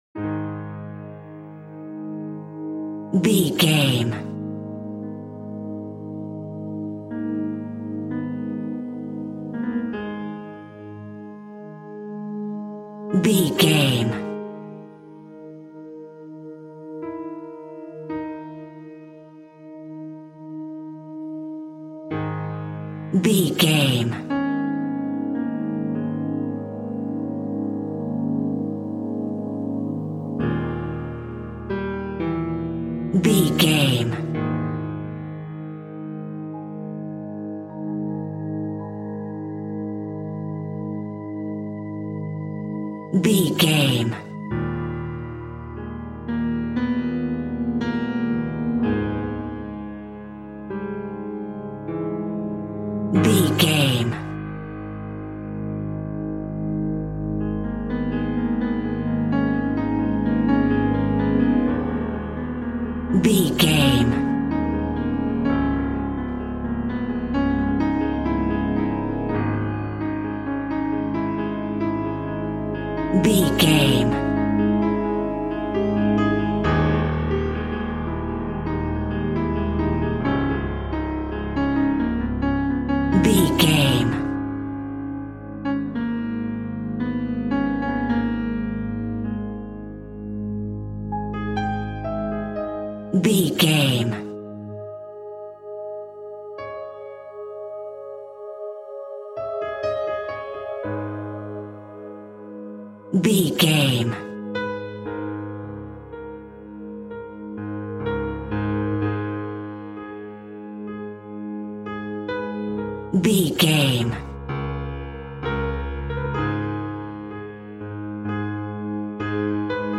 Aeolian/Minor
G#
Slow
ominous
suspense
haunting
eerie
piano
spooky
synth
ambience
pads
eletronic